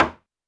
Drums_K4(60).wav